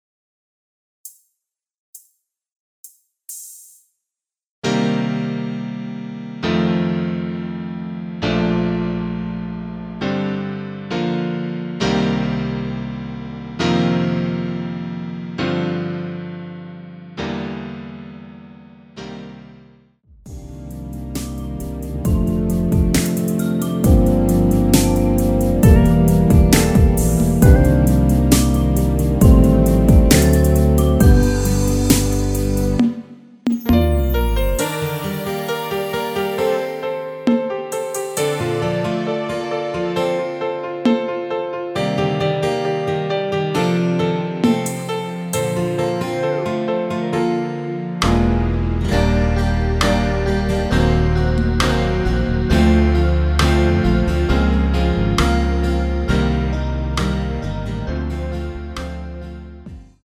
남성분이 부르실수 있는 키로 제작 하였습니다.(미리듣기 참조)
Bb
앞부분30초, 뒷부분30초씩 편집해서 올려 드리고 있습니다.